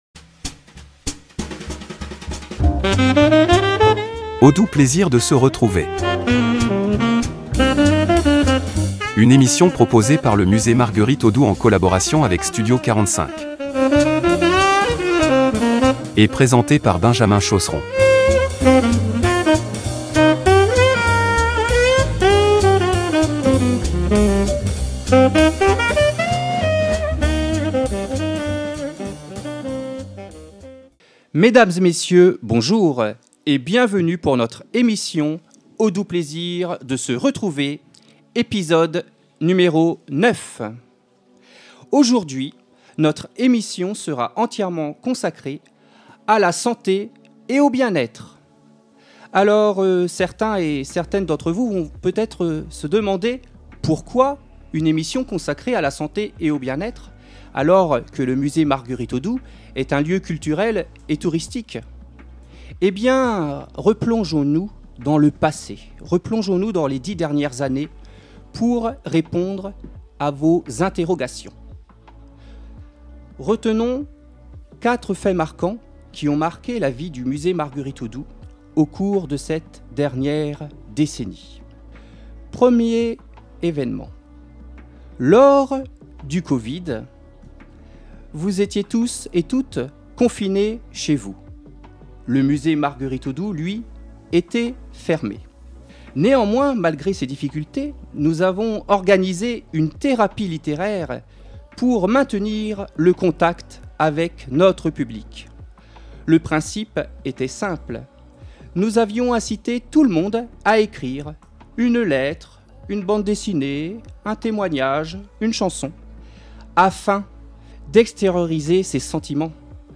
Une émission enregistrée à Aubigny-sur-Nère , en partenariat avec : Syndicat Mixte du Pays Sancerre Sologne – Communauté de Communes Sauldre et Sologne – Ville d’Aubigny-sur-Nère